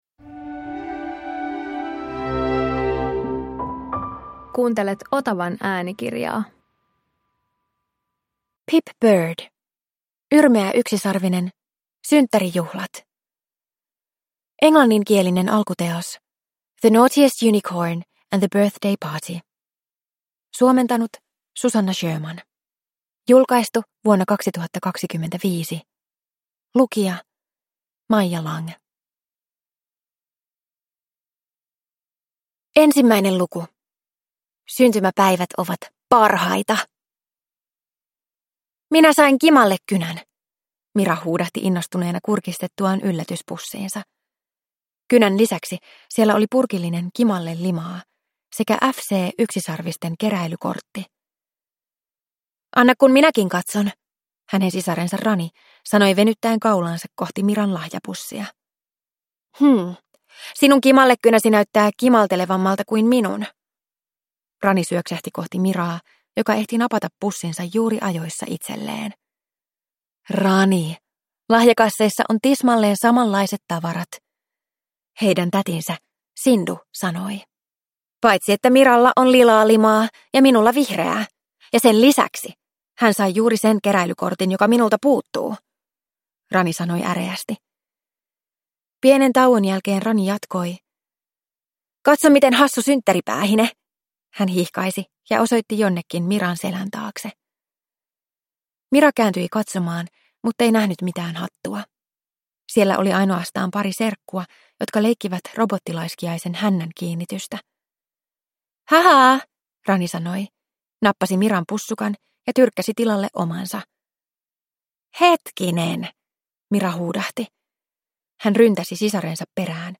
Yrmeä yksisarvinen - Synttärijuhlat – Ljudbok